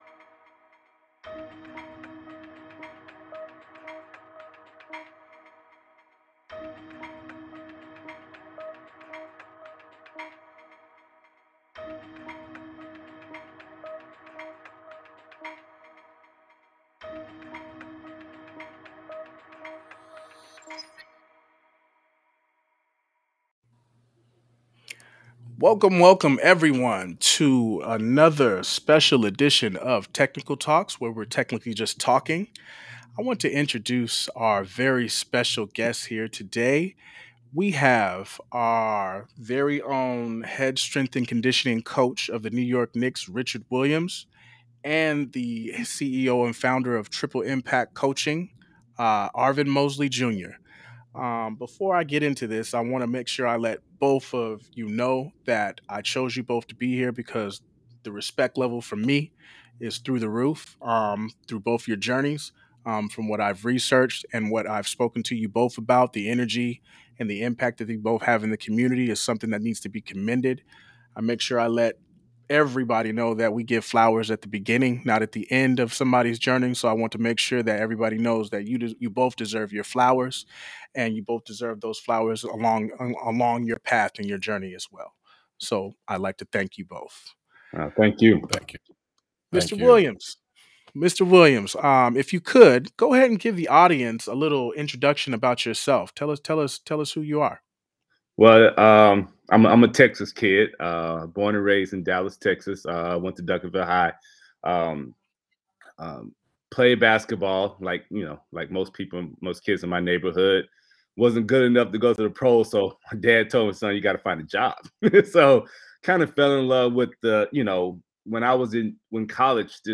We believe “Tech-nical Talks” is a curation of thought that will create inspiration, impact, and instruction through casual conversation.